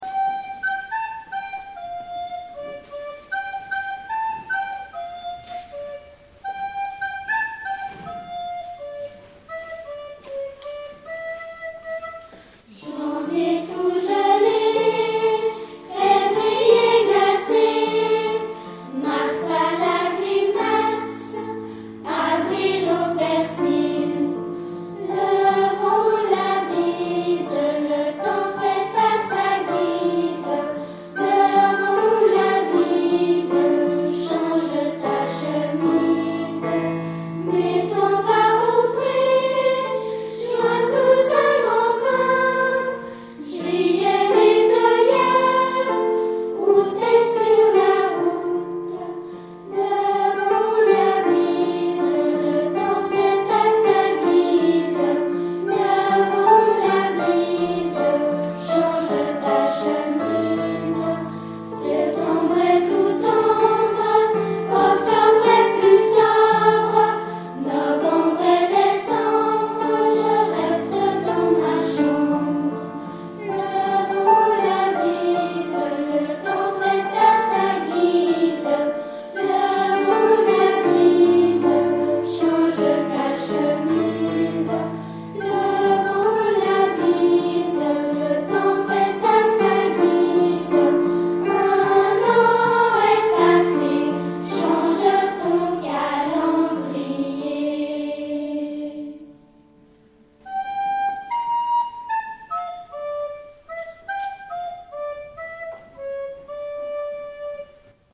Concert de l'Avent (Arconciel) - Choeur d'enfants La Voix du Gibloux
CHOEUR DES GRANDS